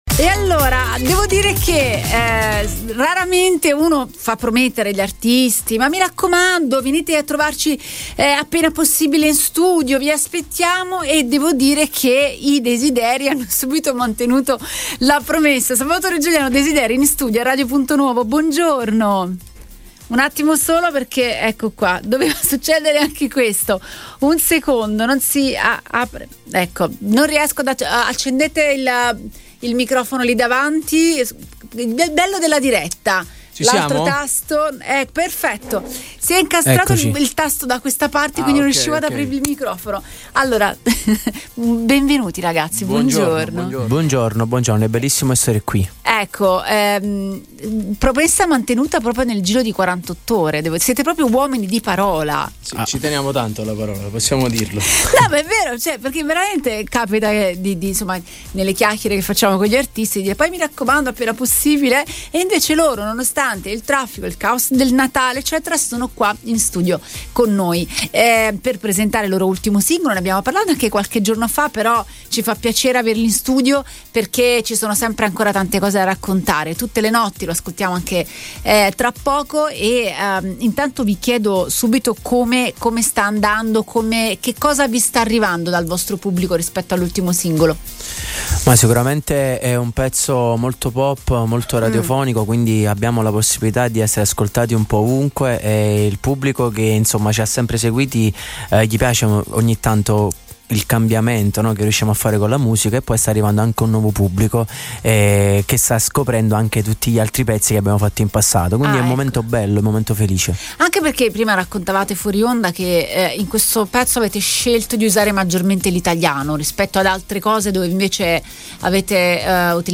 • “Insieme”: Il brano che chiude l’intervista, un inno al loro legame di fratellanza che va oltre la collaborazione artistica.